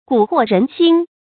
gǔ huò rén xīn
蛊惑人心发音
成语正音 蛊，不能读作“chónɡ”。